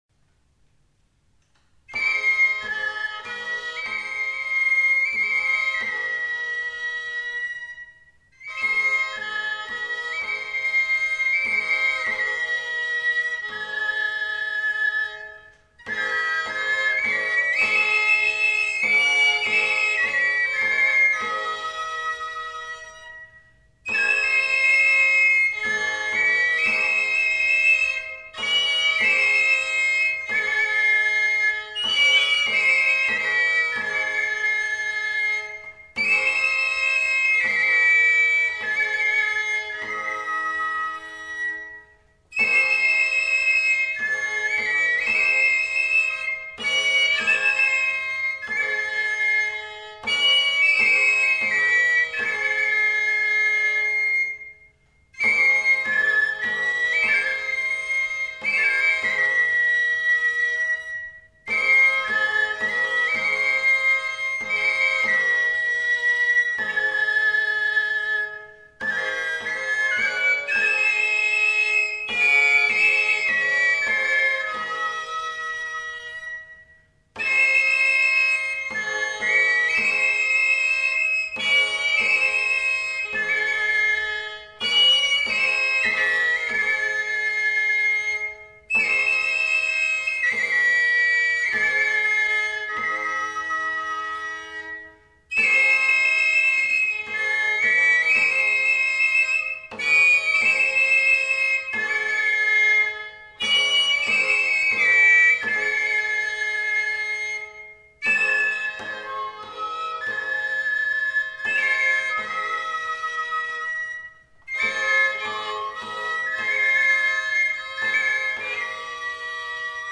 Aire culturelle : Béarn
Lieu : Bielle
Genre : morceau instrumental
Instrument de musique : violon ; flûte à trois trous ; tambourin à cordes